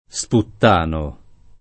sputtano [ S putt # no ]